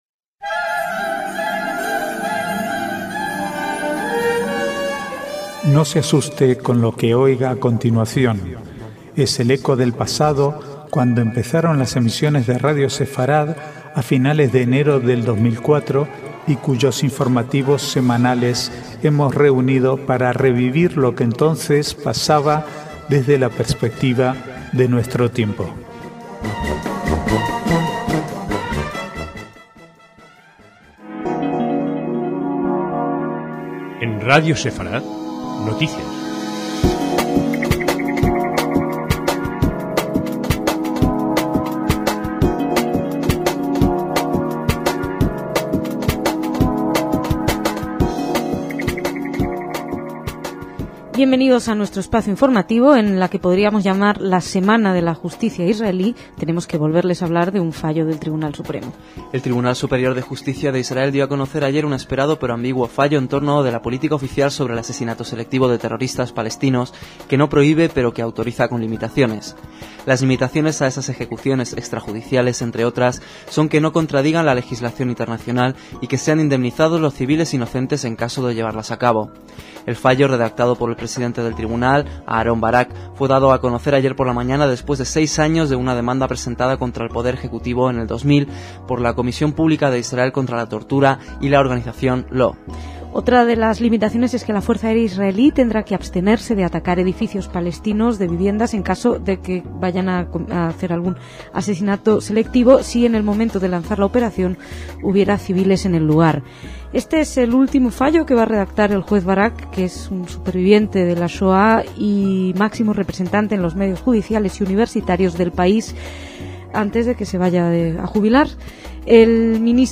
Archivo de noticias del 15 al 20/12/2006